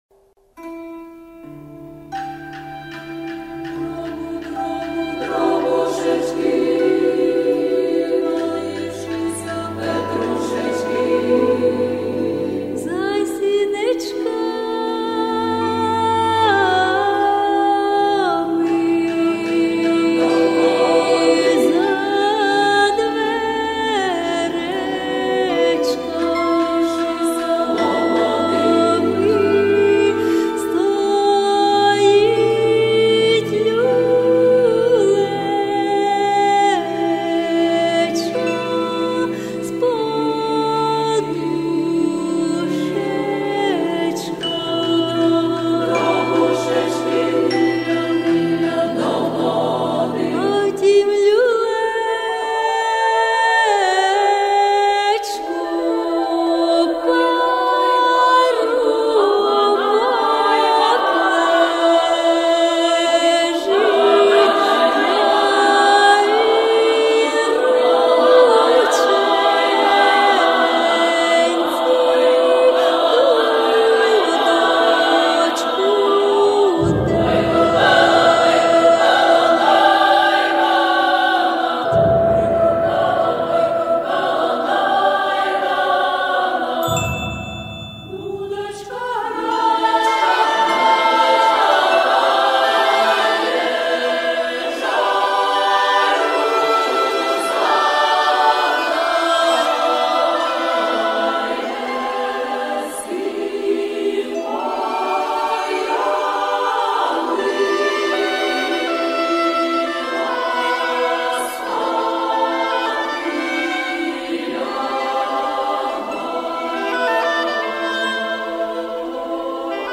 На  щастя,  збереглася  фонограма  першого  запису.
Запис  кінця  70-х  років.